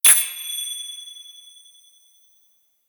Golpe de crótalos normal
percusión
crótalo
golpe